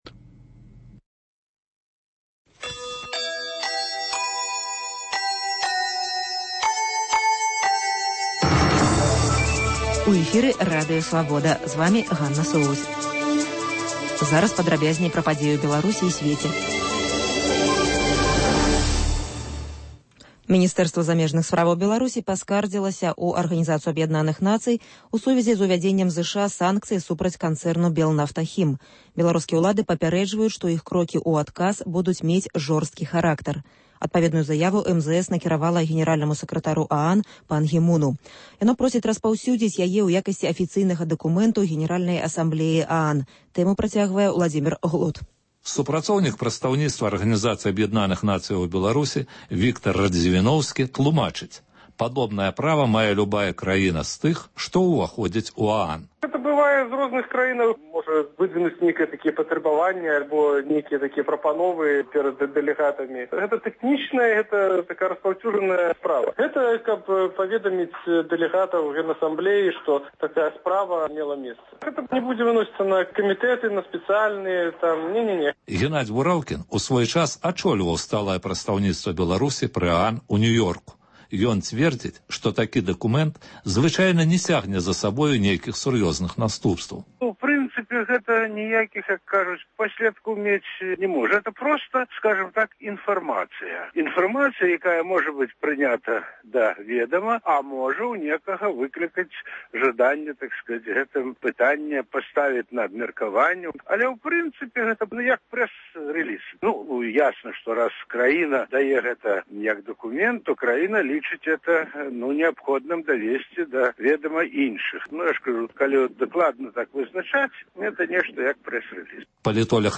Паведамленьні нашых карэспандэнтаў, галасы слухачоў, апытаньні на вуліцах беларускіх гарадоў і мястэчак.